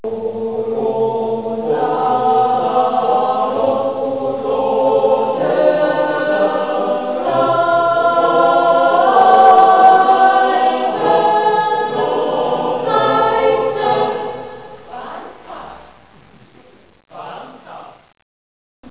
Entschuldigt die Qualität.
Aber Spaß gemacht hat's: das Singen und Tanzen, und wahrscheinlich auch das Hören und Sehen.